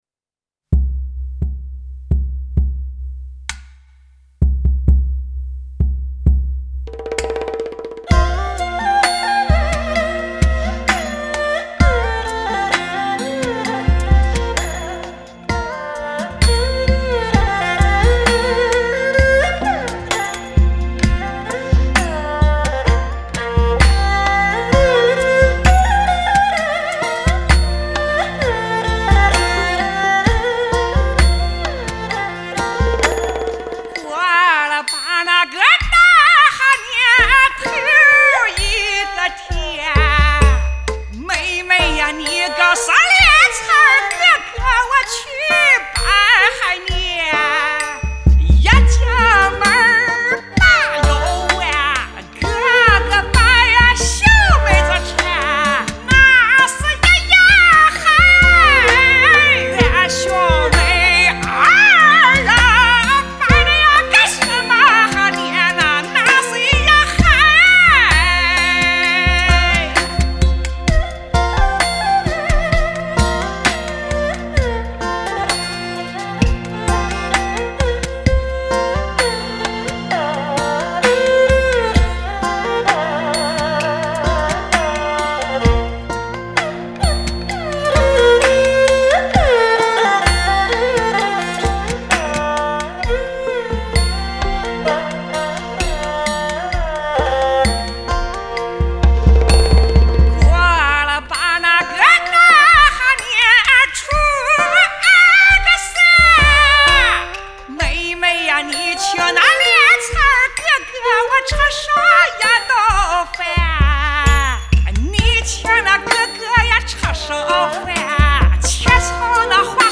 声音很特别
乡土味特浓，真喜欢这张专辑！
原汁原味的西部民歌，喜欢。